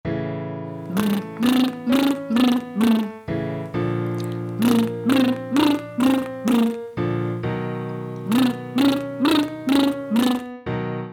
Rozśpiewka
Śpiewamy
przykład z wokalem Brr